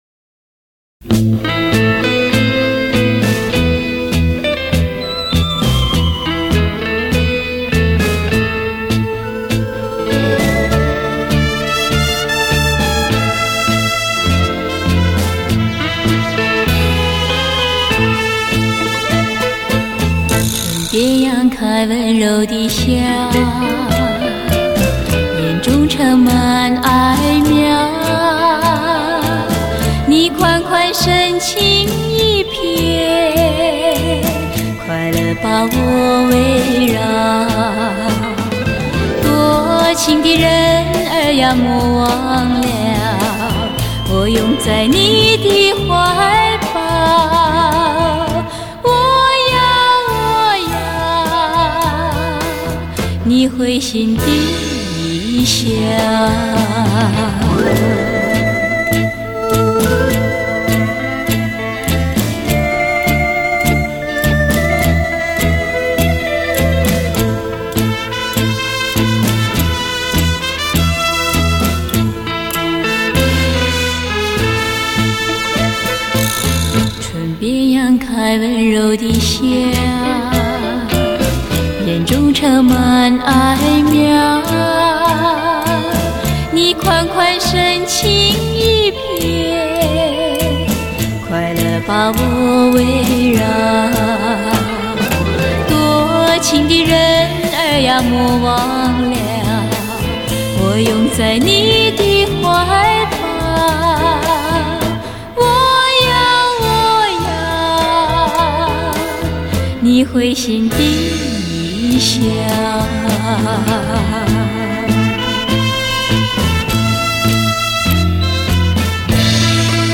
数码调音录制